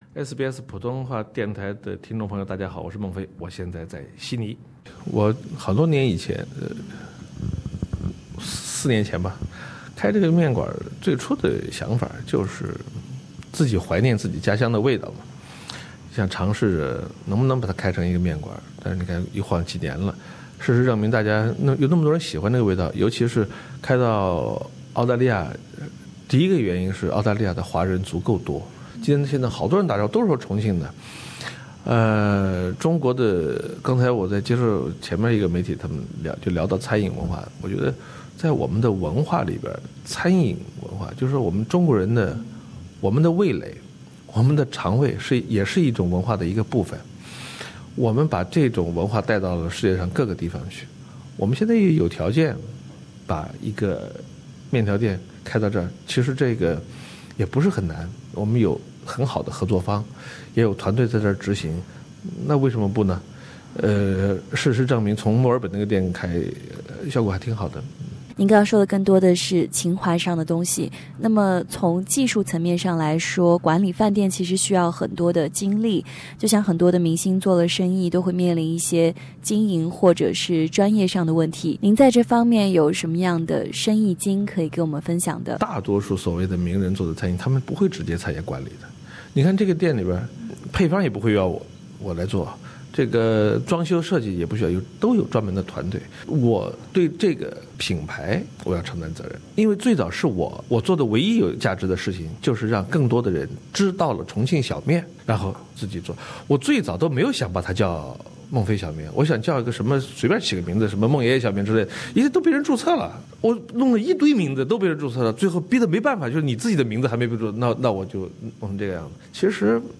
【SBS专访】"非诚勿扰“主持人孟非谈“新相亲时代”